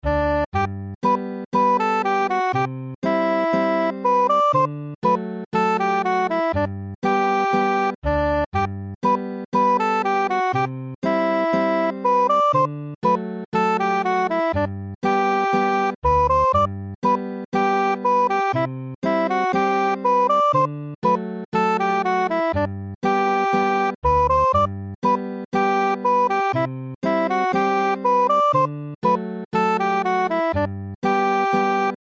Chwarae'r alaw yn G